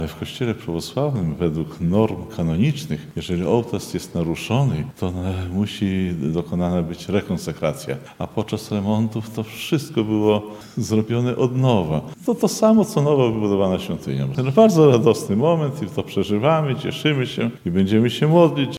– To dla nas wręcz historyczne wydarzenie – mówi ordynariusz diecezji lubelsko-chełmskiej arcybiskup Abel.